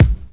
• Nineties Rap Kick One Shot B Key 484.wav
Royality free kickdrum sample tuned to the B note. Loudest frequency: 417Hz
nineties-rap-kick-one-shot-b-key-484-18d.wav